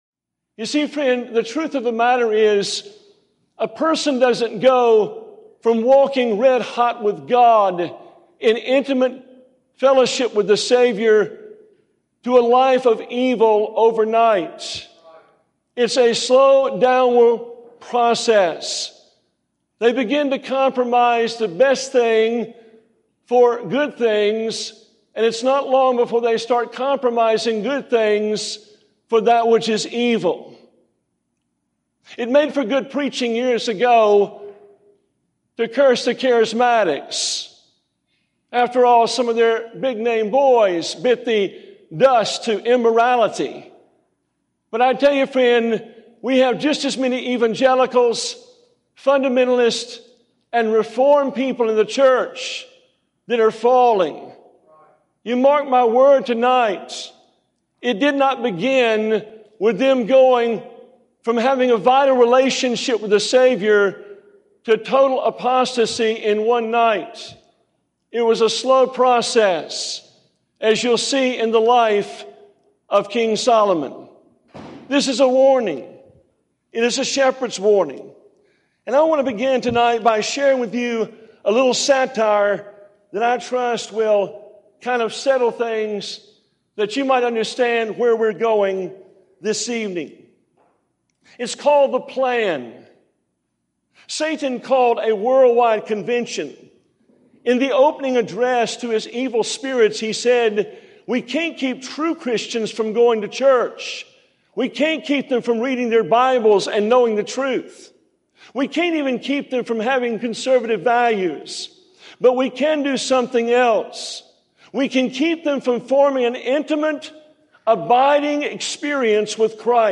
5:23 | Excerpt | Satan will call all of his hordes together to keep Christians from maintaining an intimate abiding experience with Christ.